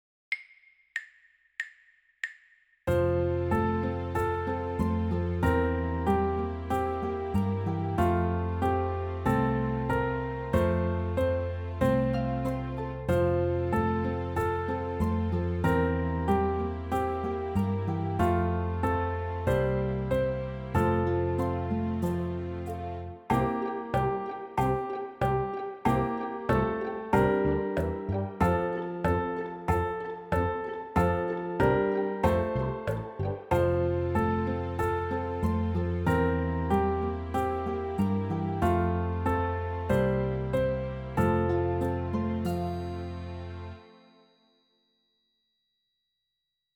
Early Elem